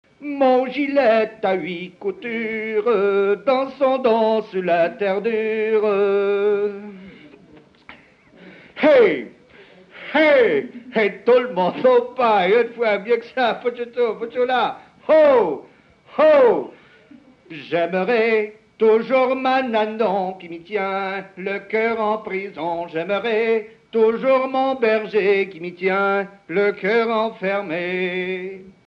Mémoires et Patrimoines vivants - RaddO est une base de données d'archives iconographiques et sonores.
Genre énumérative
Catégorie Pièce musicale inédite